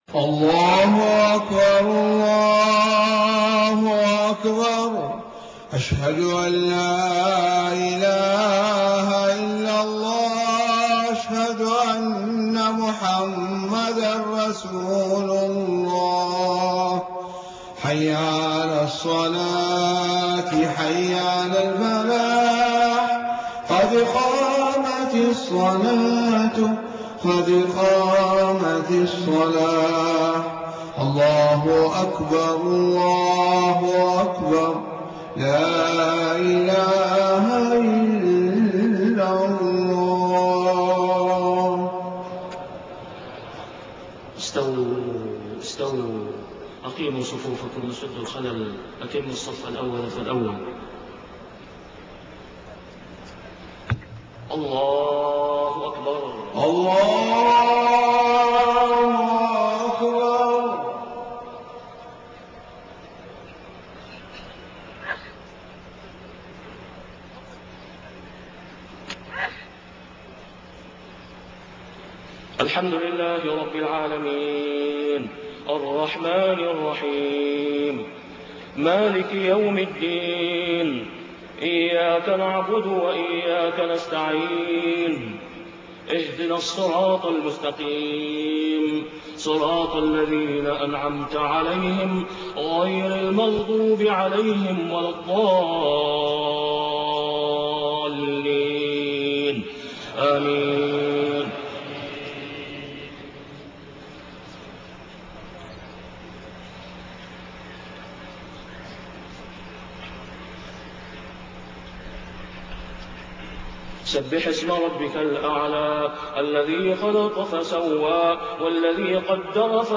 صلاة الجمعة 21 صفر 1431هـ سورتي الأعلى و الغاشية > 1431 🕋 > الفروض - تلاوات الحرمين